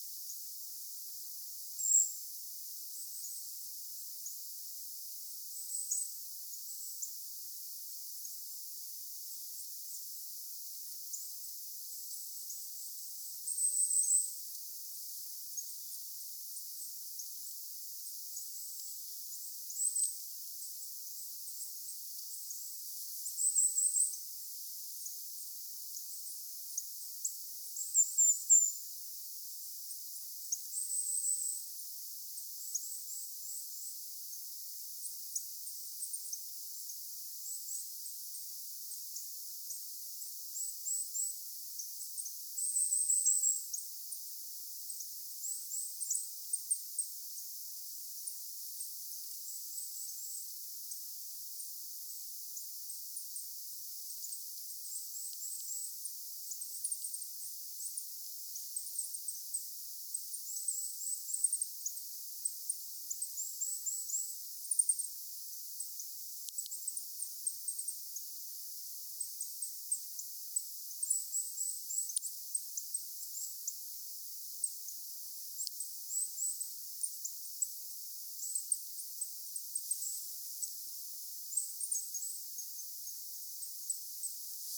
puukiipijälinnun pieniä ääniä
sen ruokaillessa
kuvien_puukiipijan_tuollaisia_pienia_ruokailun_aikana_pitamia_aania.mp3